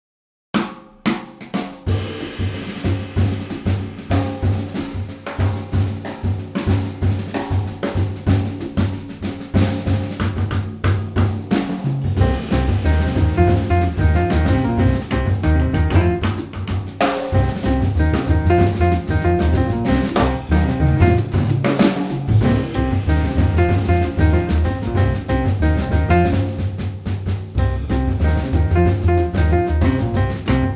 piano
contrabbasso
batteria